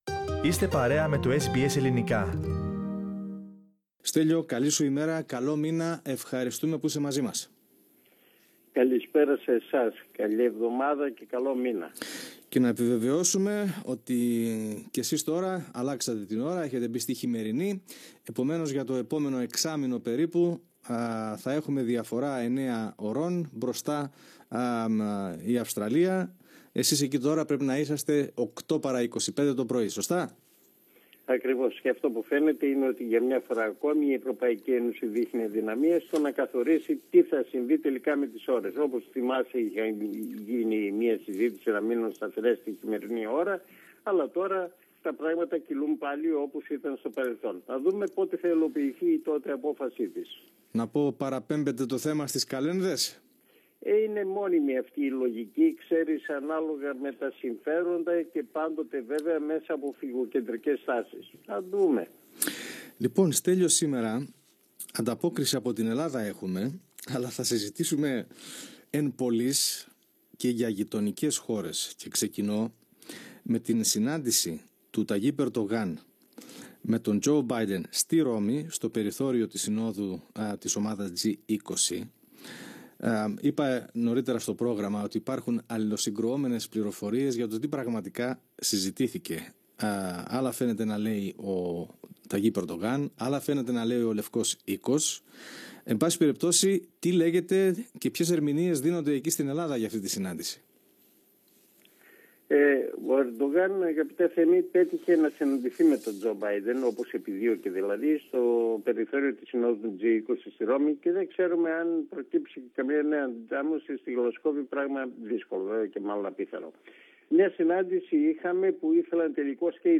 Πατήστε PLAY για να ακούσετε την ανταπόκριση του SBS Greek/SBS Ελληνικά από την Ελλάδα.